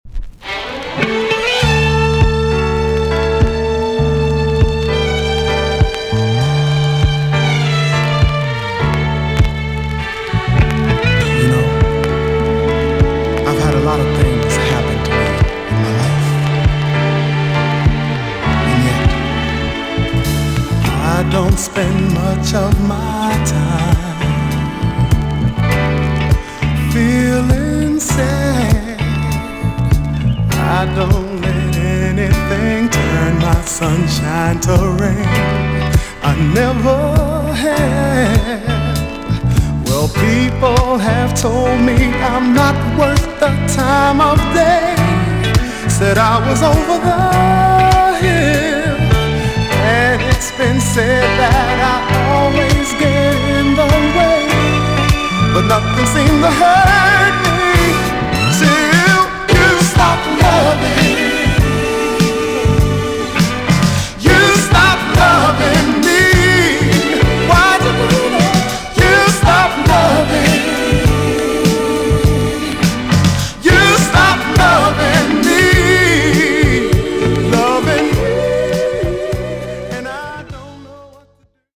EX-~VG+ 少し軽いチリノイズがありますが良好です。
1981 , JAMAICAN SOUL RECOMMEND!!